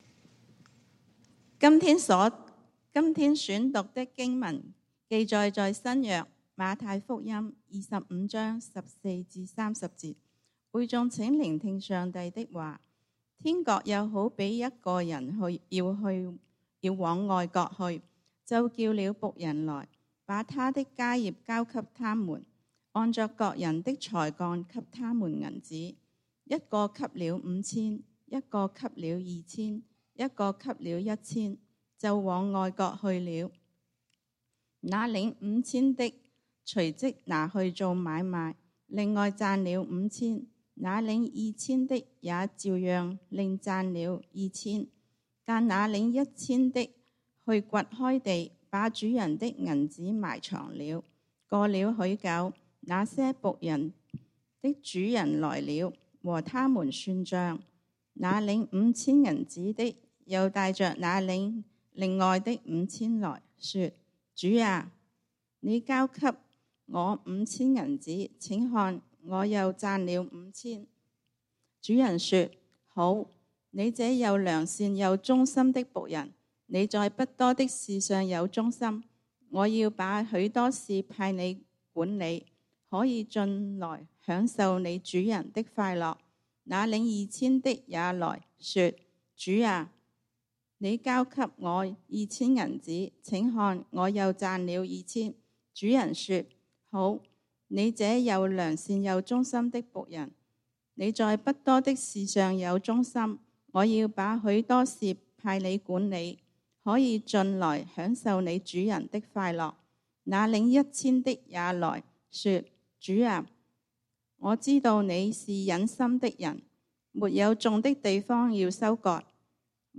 講道經文：《馬太福音》Matthew 25:14-30 本週箴言：《腓立比書》Philippians 4:4-5 「你們要靠主常常喜樂。